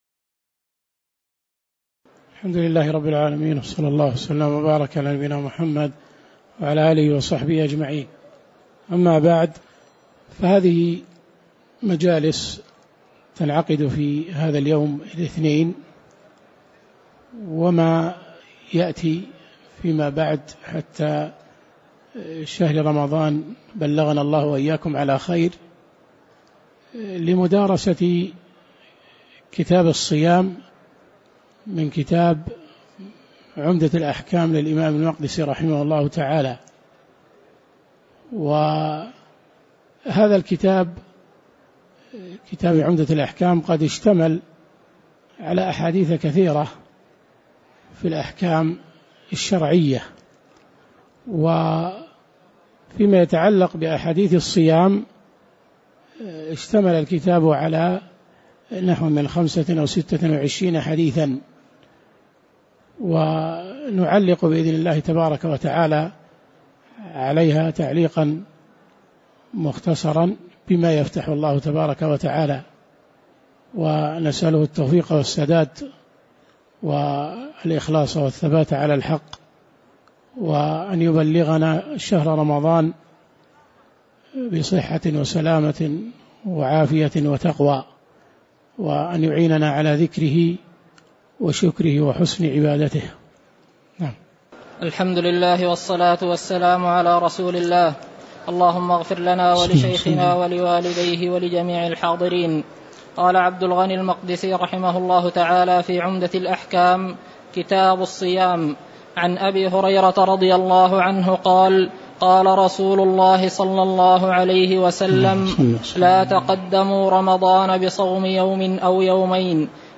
تاريخ النشر ٣٠ رمضان ١٤٣٩ هـ المكان: المسجد النبوي الشيخ